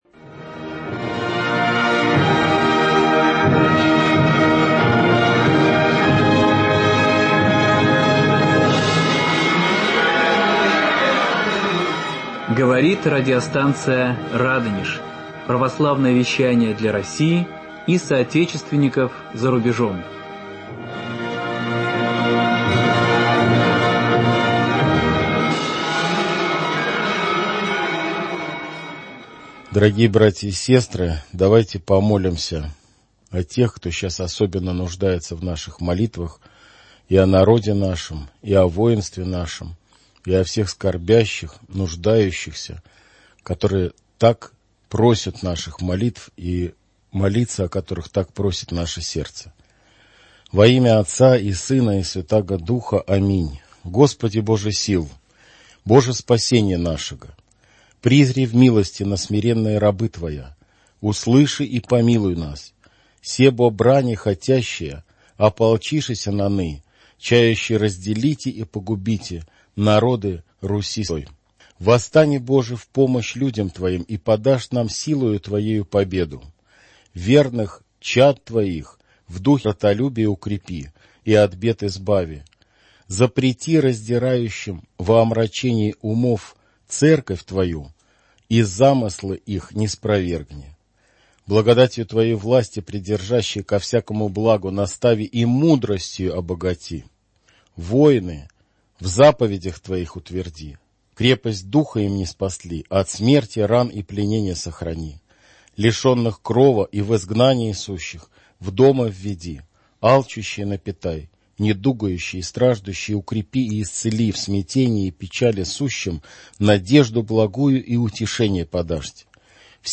Эфир от 03.11.2023 22:03 | Радонеж.Ру